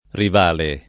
rivale [ riv # le ] s. m. e f.